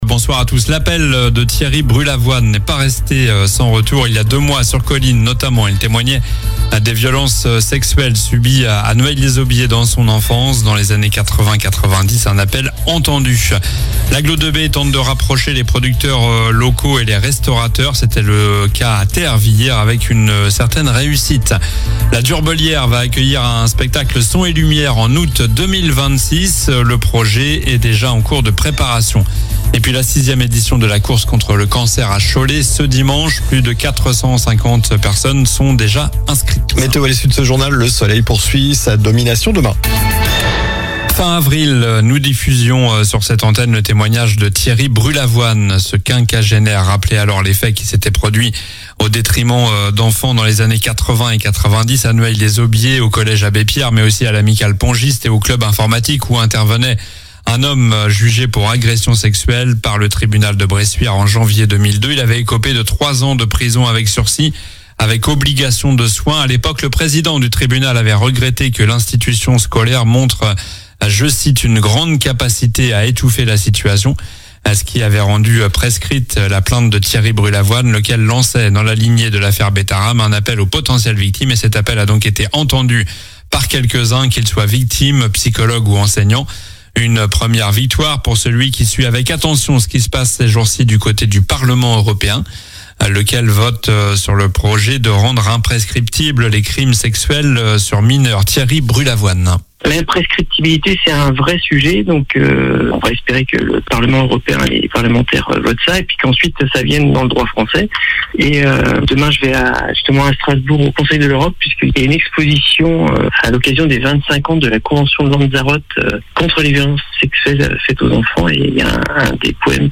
Journal du mardi 17 juin (soir)